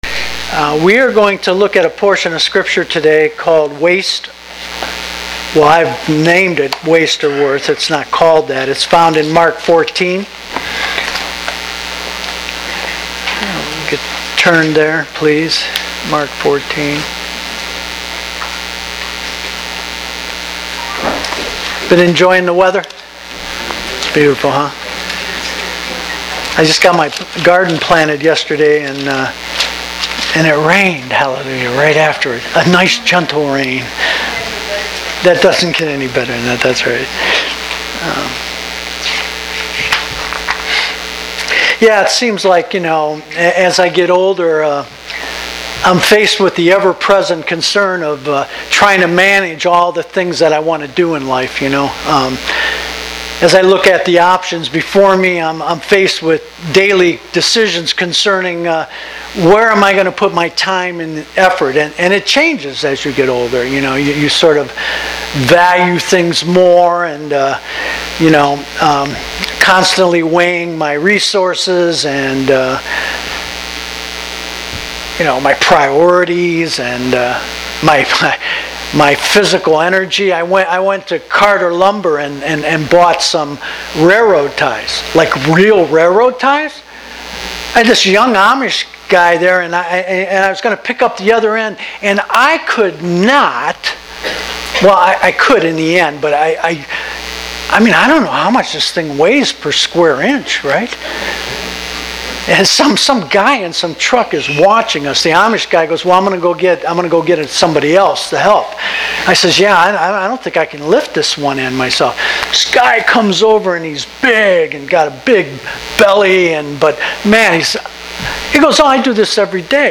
Grace Evangelical Bible Church » Service Type » Sunday Morning Service